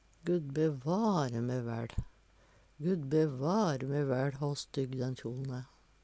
Eksempel på bruk Guddbevare me væL hå stygg den kjol`n æ. Tilleggsopplysningar Om ein og har med væL i uttrykket er dette ei forsterking av kraftuttrykket. Tonefallet er viktig.